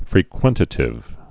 (frē-kwĕntə-tĭv) Grammar